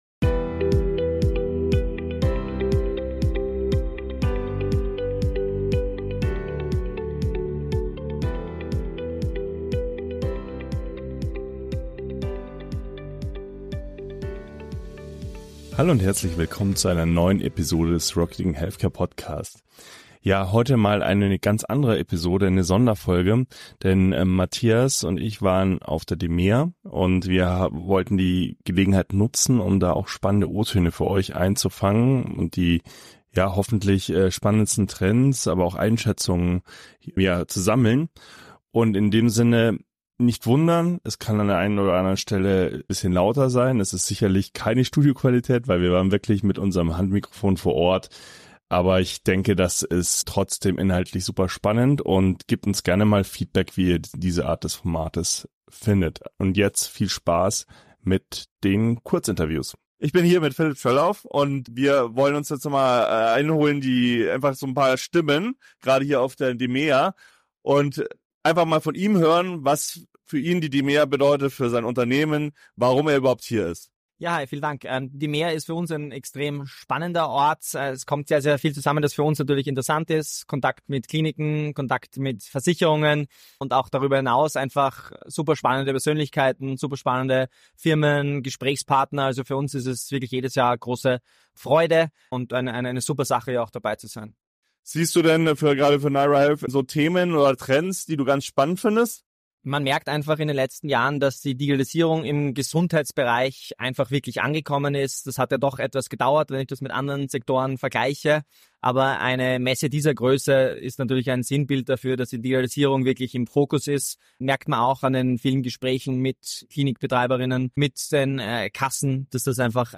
RHC 010: Trends, Einschätzungen und spannende O-Töne live von der DMEA ~ Rocketing Healthcare: Startups & Innovationen im Gesundheitswesen Podcast